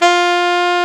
SAX A.MF F09.wav